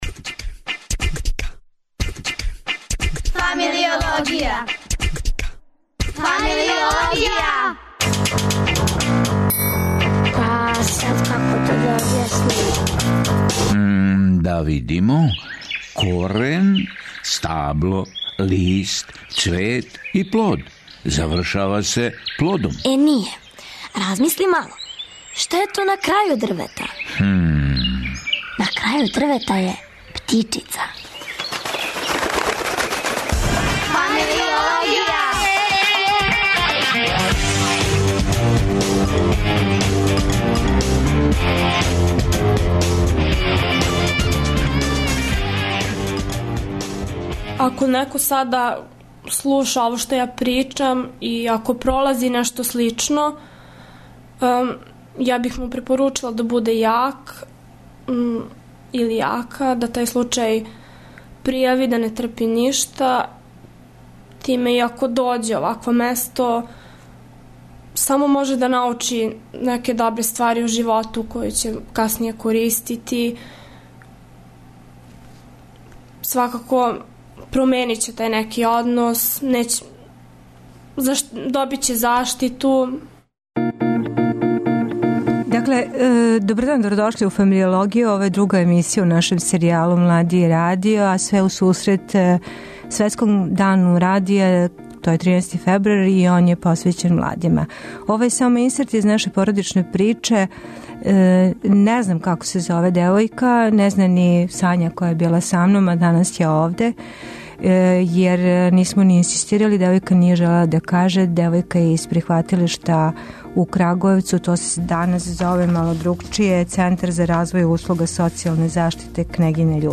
До 13. фебруара, када је Светски дан радија - ове године одређен темом Млади и радио, у Фамилиологија ћемо разговарати о (не)видљивости младих у Србији. У данашњем сату слушаћемо Девојку.